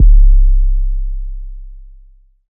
Plain808_YC.wav